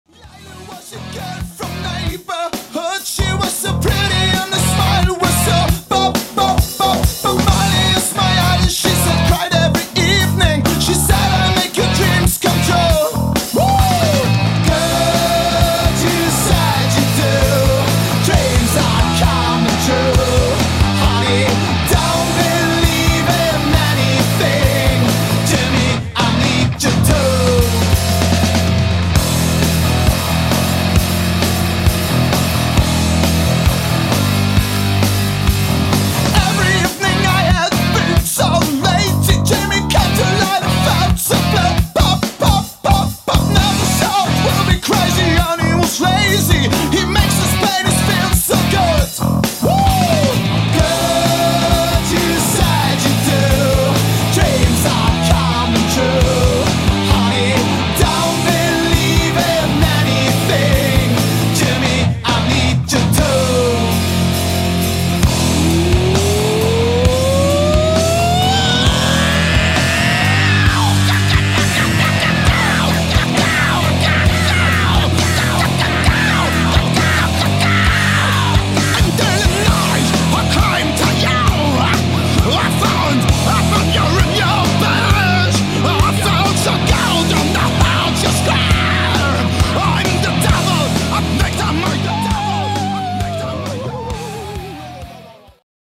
Alternative Metal, Crossover